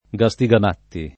vai all'elenco alfabetico delle voci ingrandisci il carattere 100% rimpicciolisci il carattere stampa invia tramite posta elettronica codividi su Facebook castigamatti [ ka S ti g am # tti ] (tosc. gastigamatti [ g a S ti g am # tti ]) s. m.